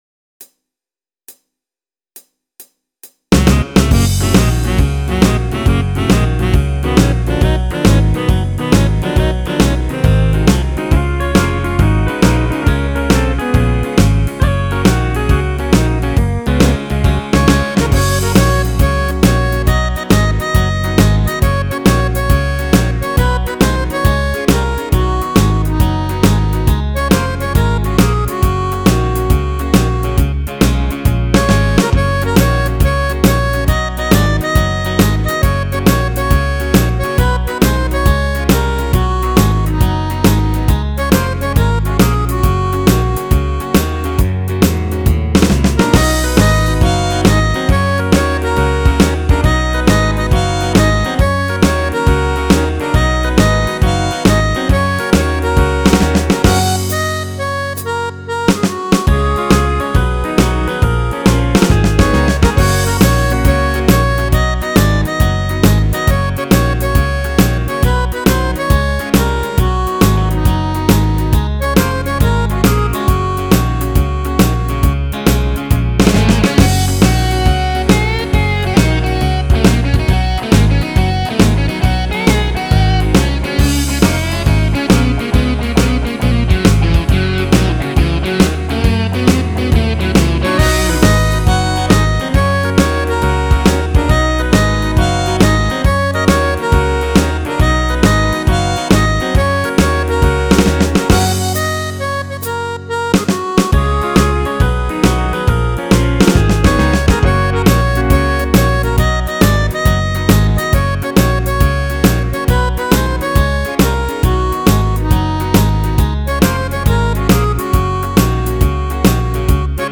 Oh, be sure to wait for all of the ending – it’s irreverent.